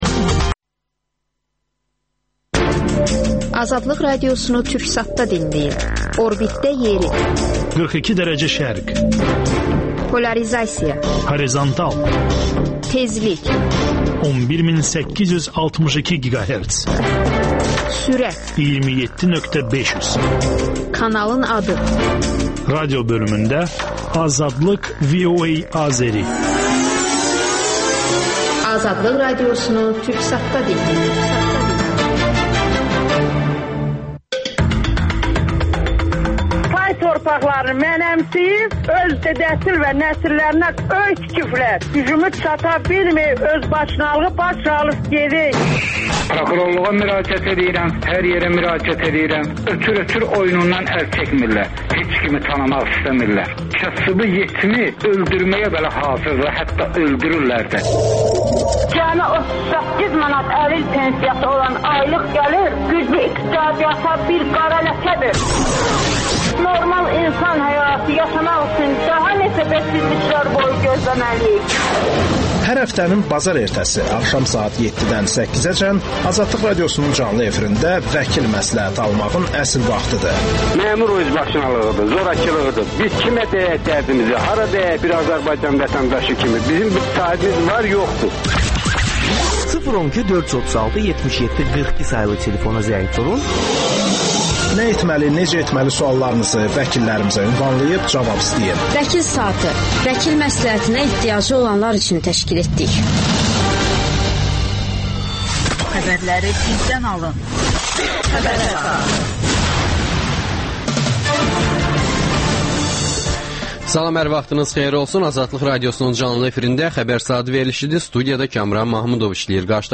AzadlıqRadiosunun müxbirləri ölkə və dünyadakı bu və başqa olaylardan canlı efirdə söz açırlar. Günün sualı: Müasir Azərbaycan Respublikasını Azərbaycan Cümhuriyyətinin davamçısı sayırsan?